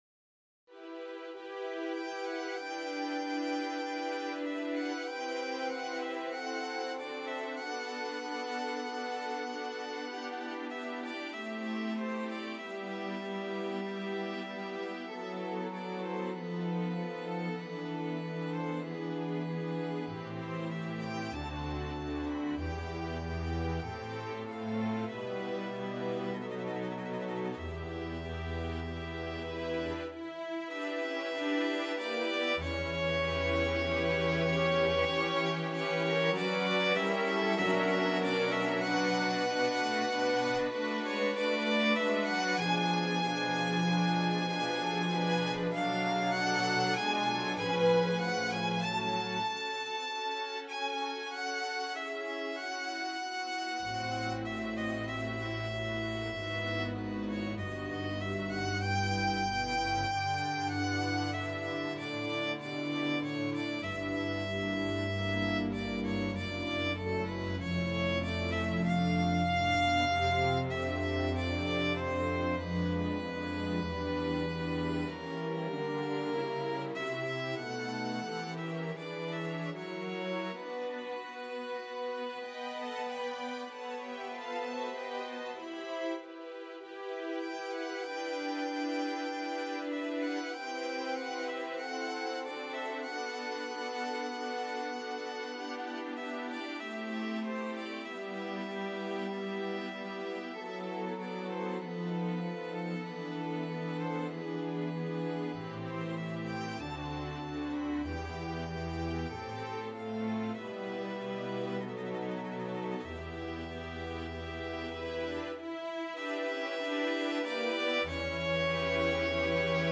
Rachmaninoff, Sergei - Vocalise, Op.34, No.14(Solo Violin and Strings)
Free Sheet music for String Ensemble
Solo ViolinViolin 1Violin 2ViolaCelloDouble Bass
4/4 (View more 4/4 Music)
E minor (Sounding Pitch) (View more E minor Music for String Ensemble )
= 48 Lentamente. Molto cantabile
Classical (View more Classical String Ensemble Music)